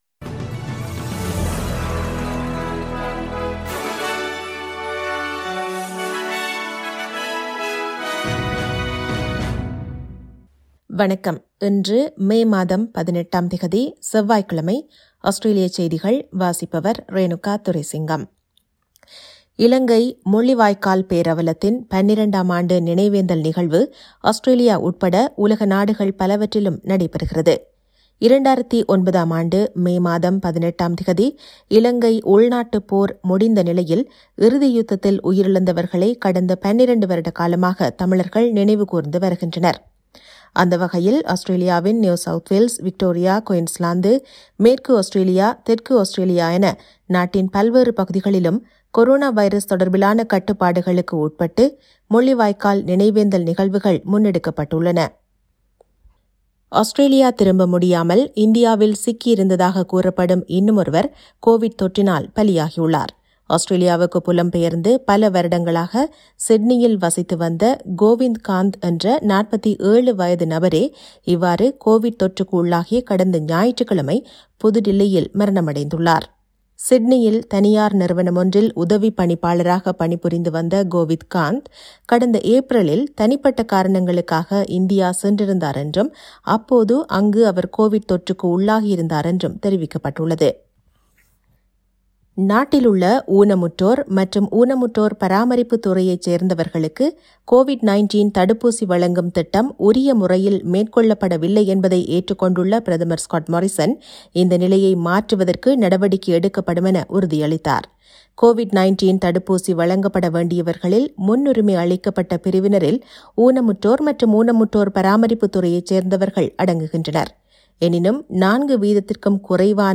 Australian news bulletin forTuesday 18 May 2021.